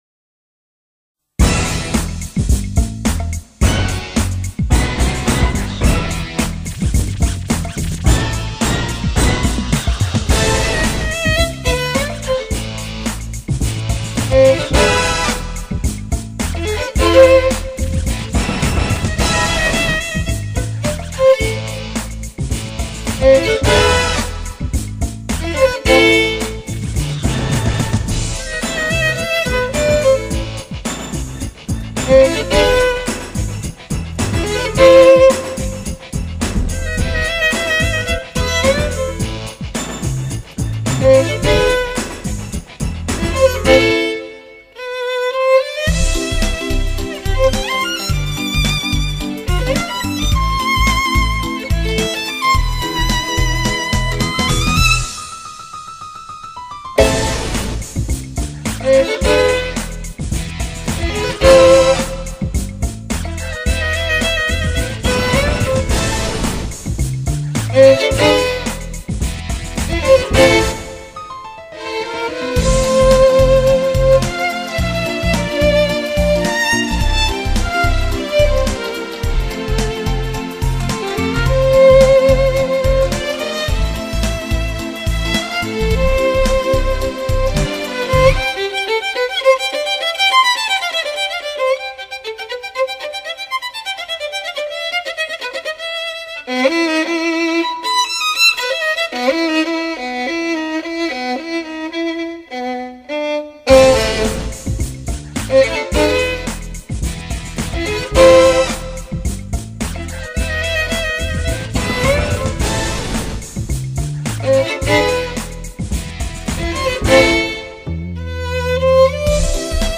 听著小提琴把流行曲拉出了另一种味道 ，